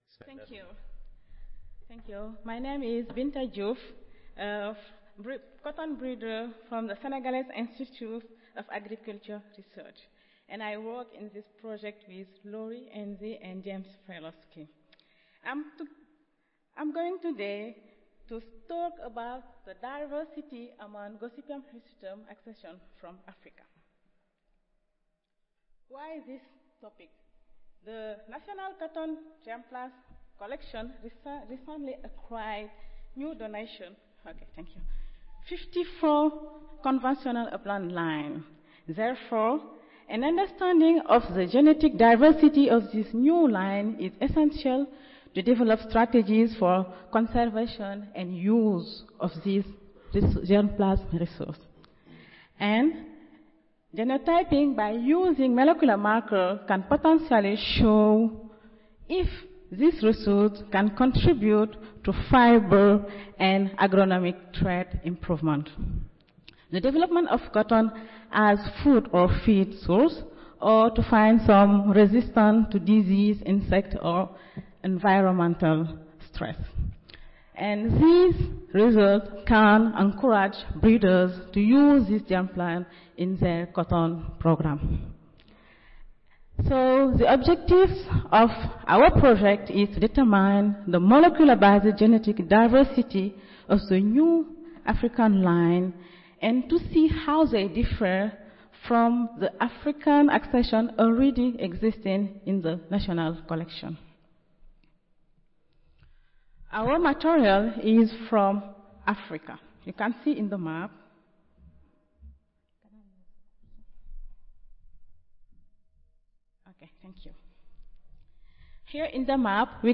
Lightning talks tied to a poster
Audio File Recorded Presentation